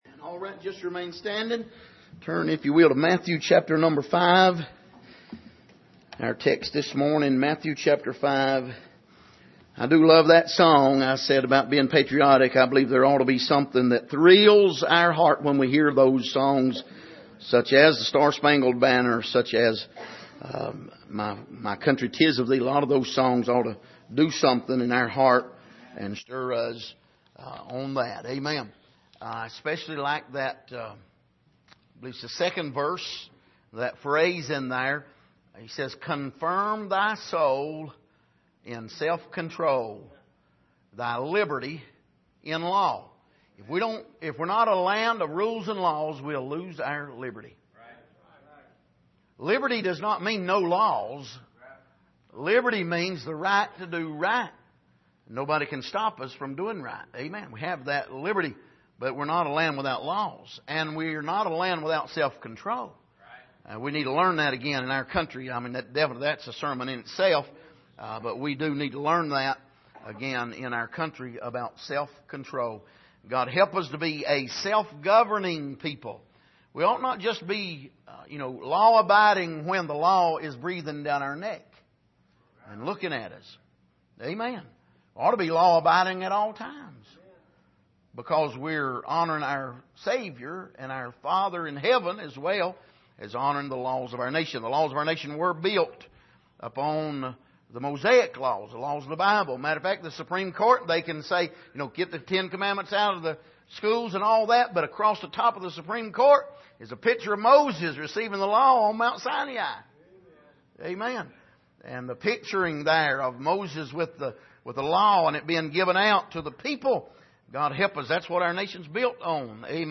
Passage: Matthew 5:1-5 Service: Sunday Morning